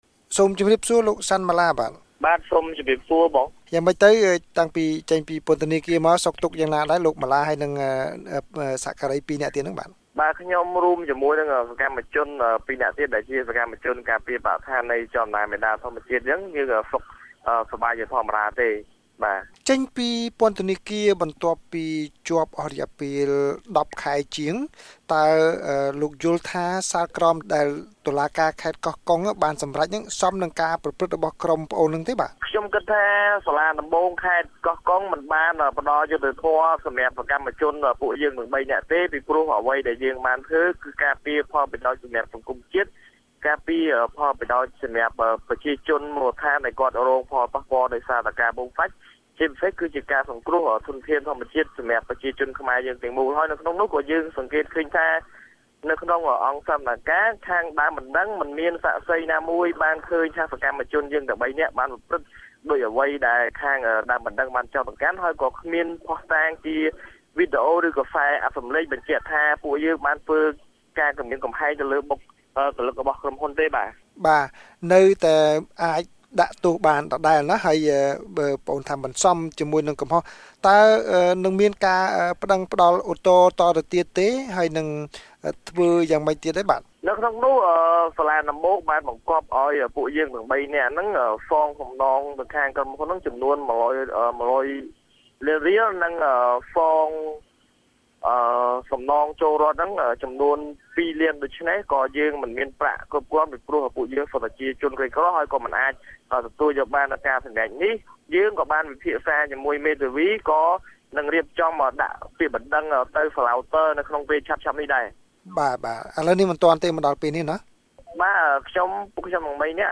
បទសម្ភាសន៍ VOA៖ សកម្មជនមូលដ្ឋានប្តេជ្ញាបន្តសកម្មភាពថ្វីបើមានការសម្លាប់លោកកែម ឡី